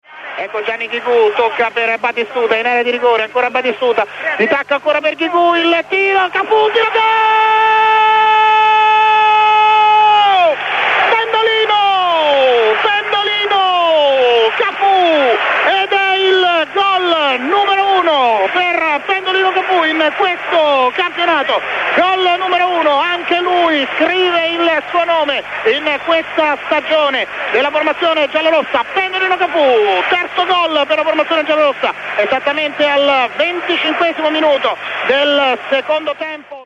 radiocronaca del goal